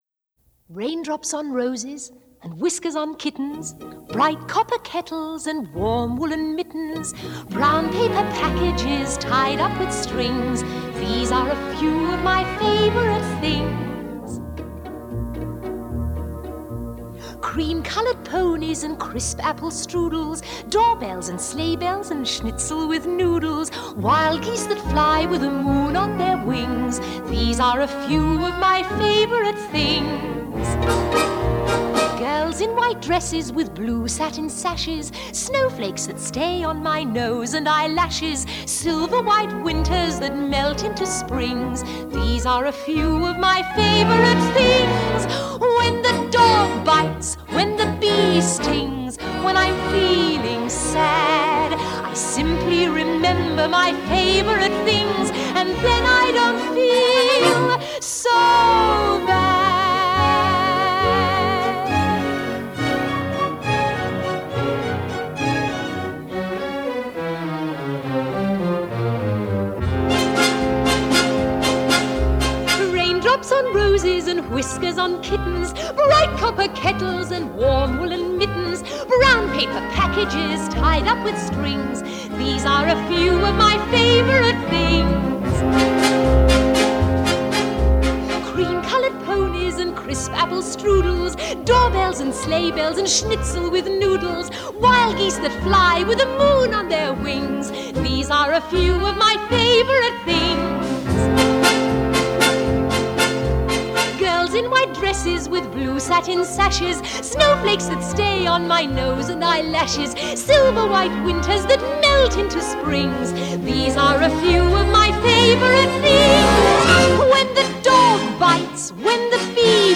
1959   Genre: Musical   Artist